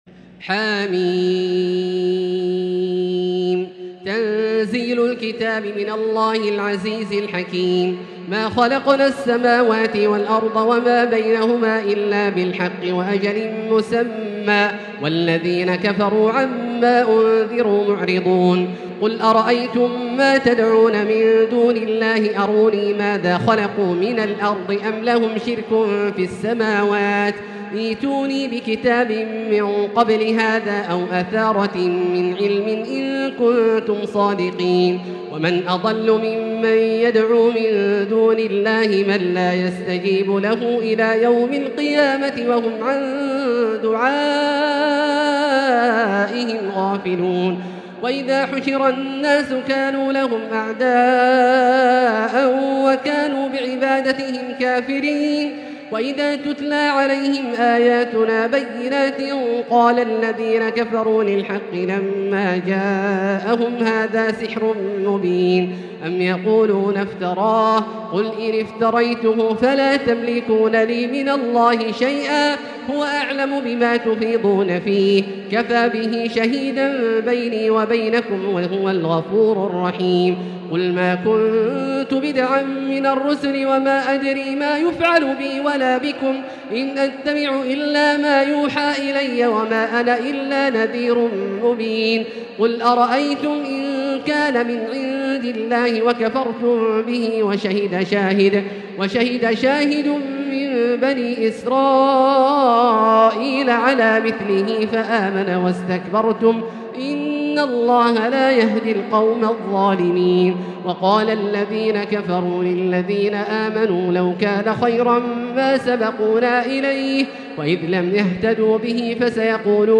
تلاوة مميزة حدرية لـ سورة الأحقاف كاملة للشيخ د. عبدالله الجهني من المسجد الحرام | Surat Al-Ahqaf > تصوير مرئي للسور الكاملة من المسجد الحرام 🕋 > المزيد - تلاوات عبدالله الجهني